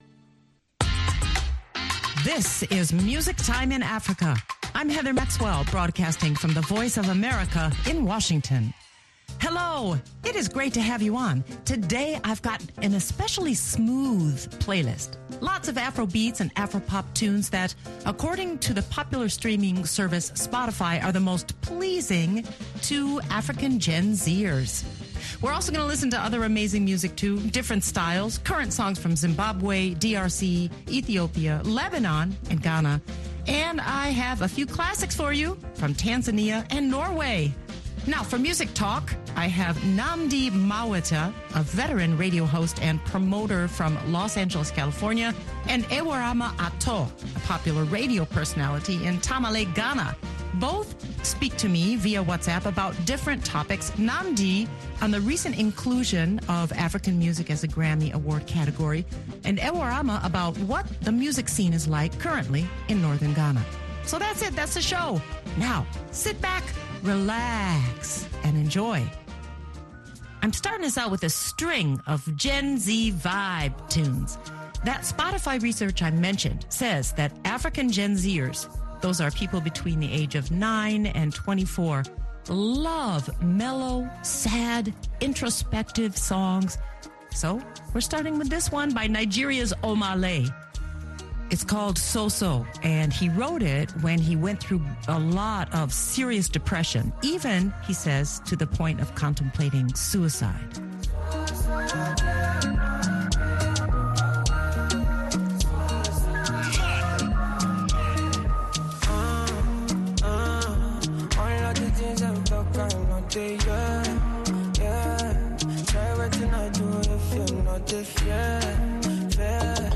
The playlist features smooth Afrobeats and Afropop tunes popular among Gen Z listeners.